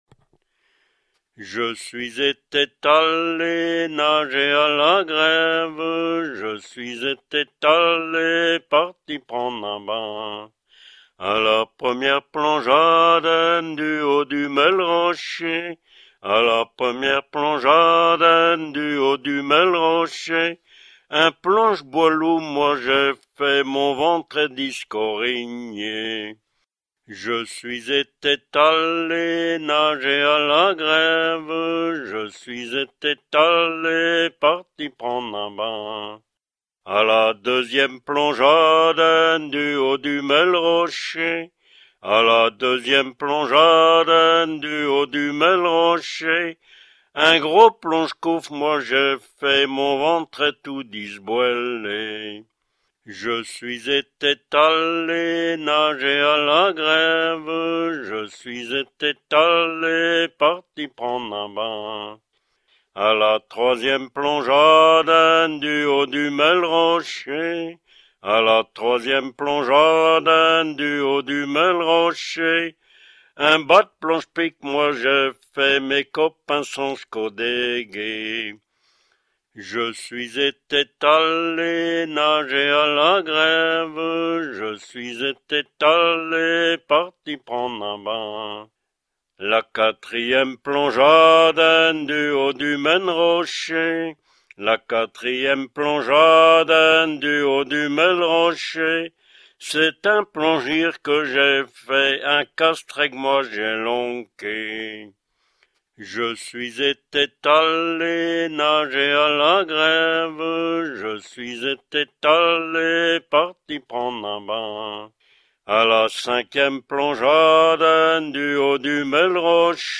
Genre strophique
Enquête Douarnenez en chansons
Pièce musicale inédite